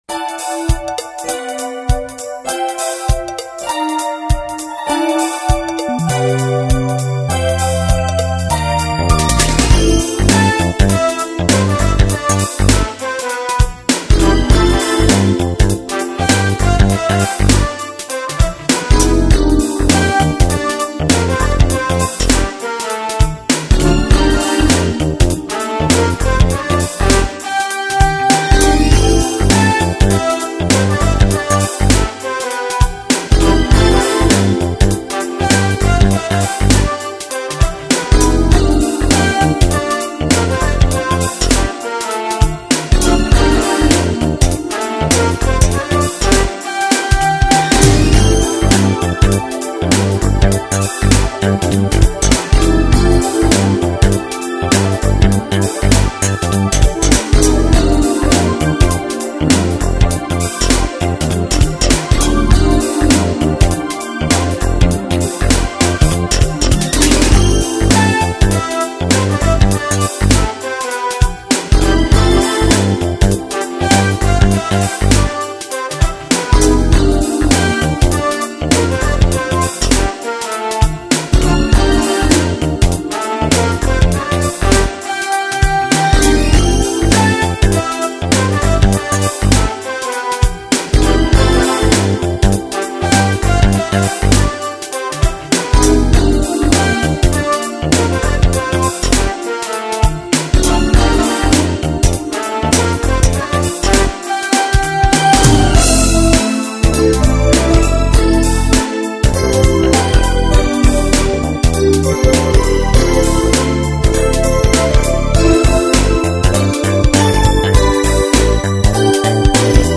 Major 明るめ 明るく軽快なアルペジオ入り